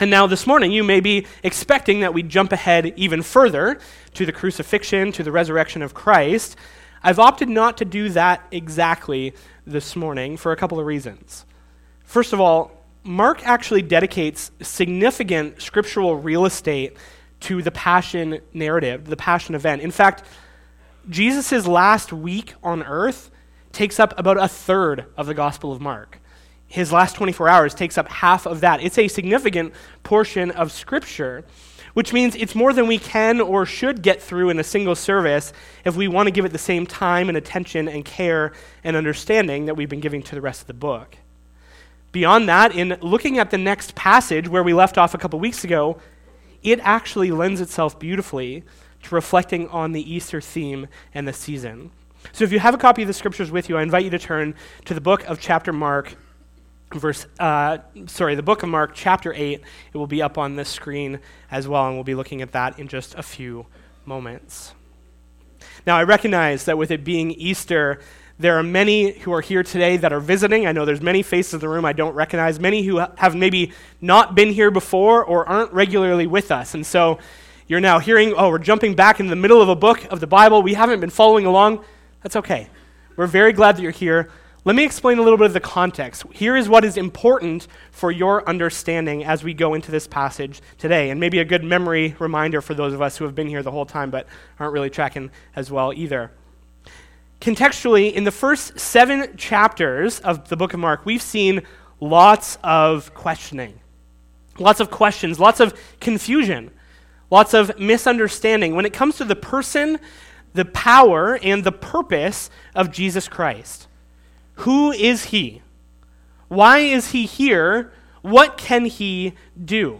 SERMONS - Community Bible Church